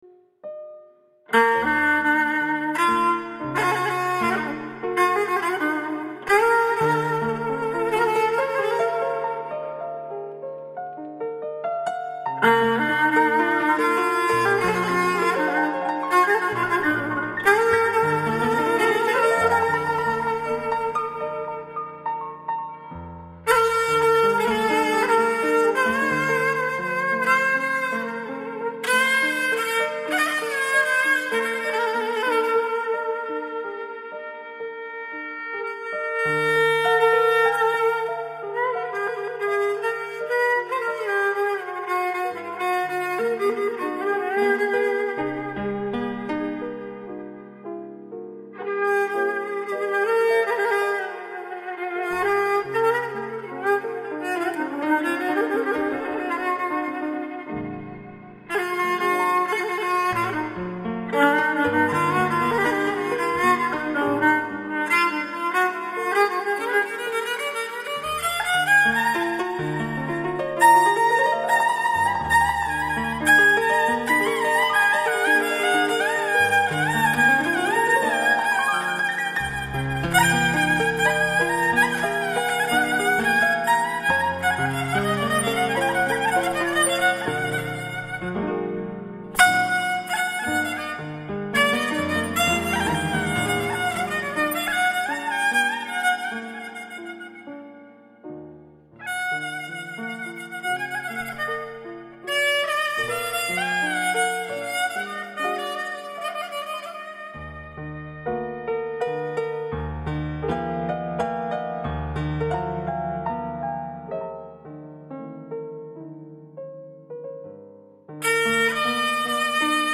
Azerbaycan xalq mahnı ve tesnifleri